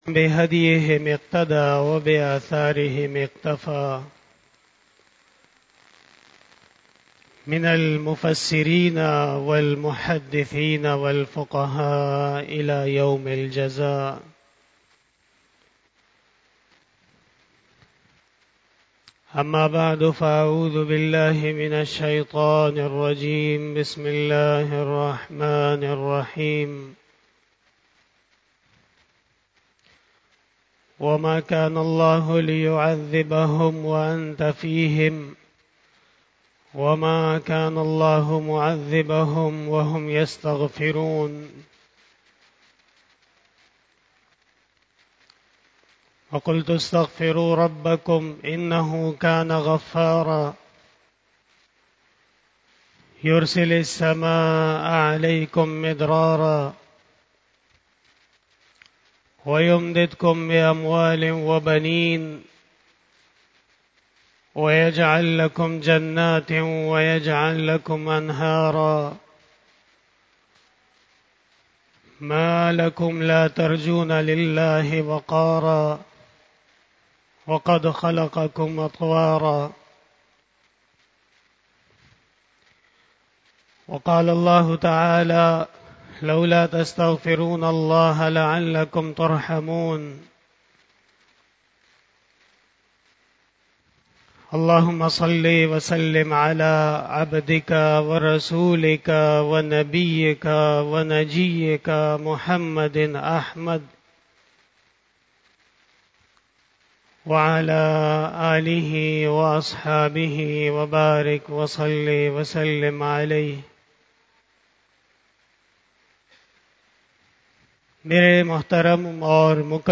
34 BAYAN E JUMA TUL MUBARAK 02 September 2022 (05 Safar 1444H)
Khitab-e-Jummah